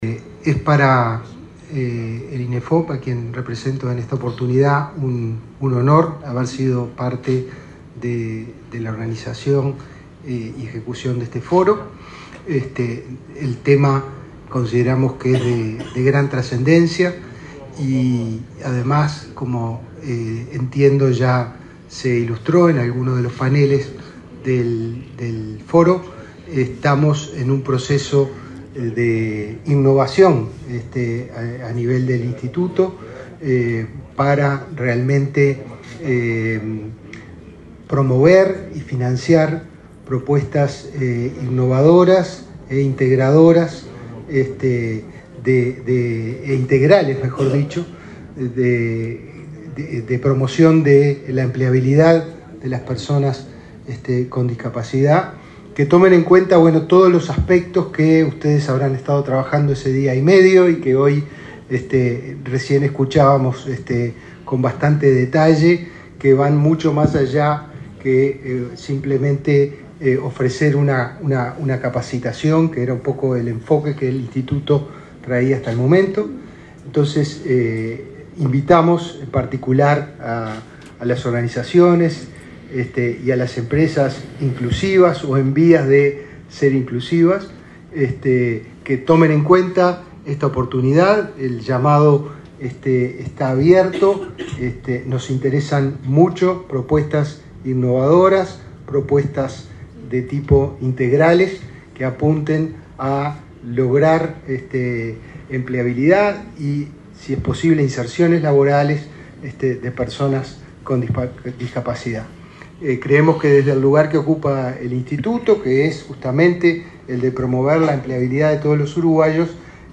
Palabras del director de Inefop
Palabras del director de Inefop 26/10/2022 Compartir Facebook X Copiar enlace WhatsApp LinkedIn El director del Instituto Nacional de Empleo y Formación Profesional (Inefop), Pablo Darscht, participó este miércoles 26 en el acto de clausura del VIII Foro Iberoamérica Incluye, realizado en Montevideo.